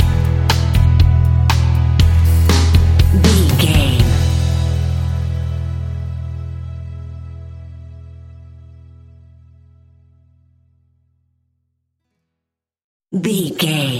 Uplifting
Ionian/Major
pop rock
fun
energetic
guitars
bass
drums
organ